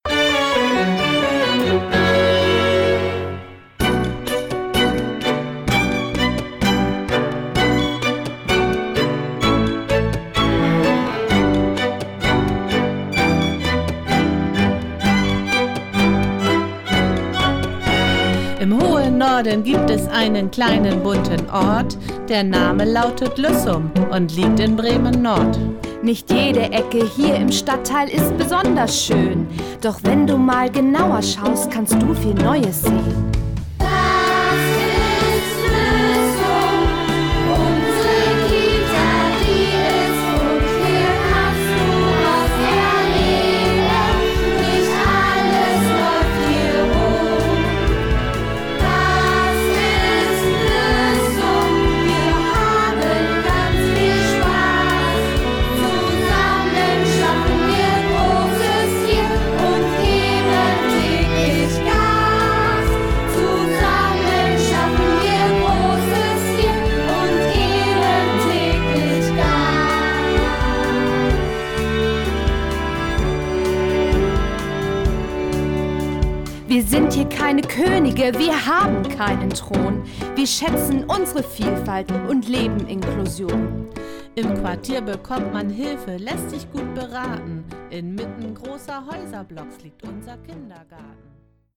mit zwei ErzieherInnen und 16 Kindern unserer Kita eingeübt
Streichquartett
Trompeter